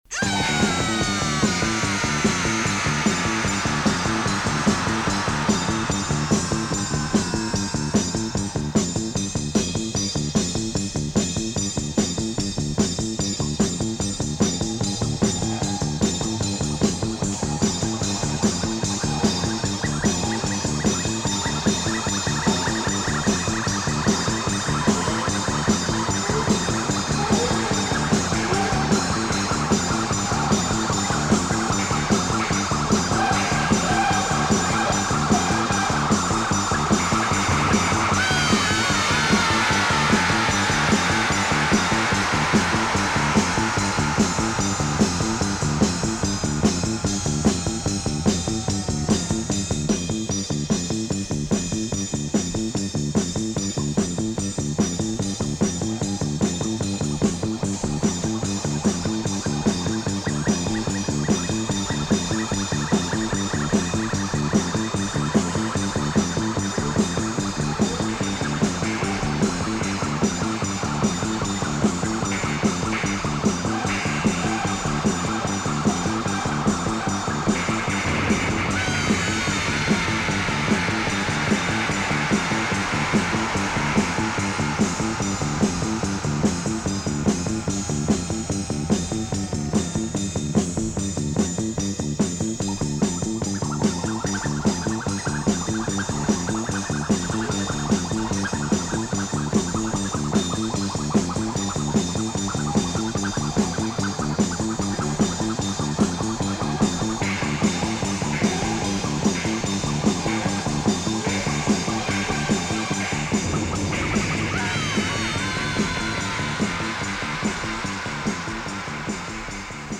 Some pretty nice psychedelic funk tunes
Soundtracks